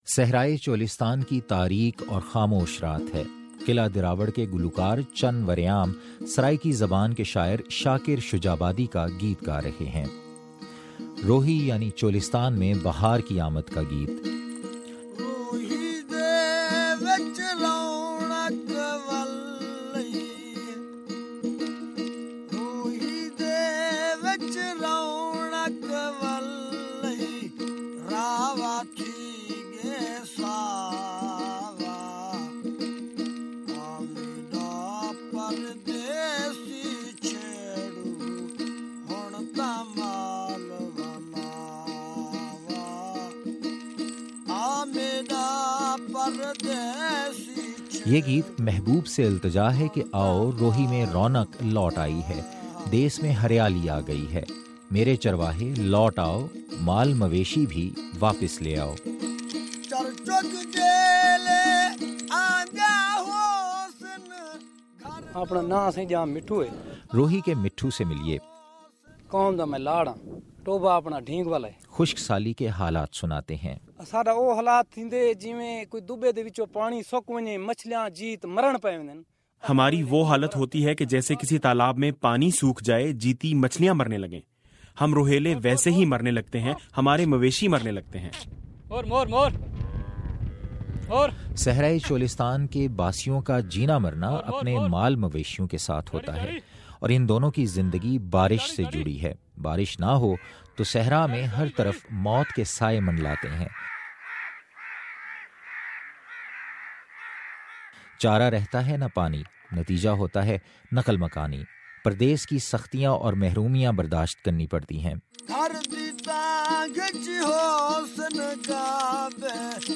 قلعہ دراوڑ سے رپورٹ